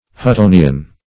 Search Result for " huttonian" : The Collaborative International Dictionary of English v.0.48: Huttonian \Hut*to"ni*an\, a. Relating to what is now called the Plutonic theory of the earth, first advanced by Dr. James Hutton.
huttonian.mp3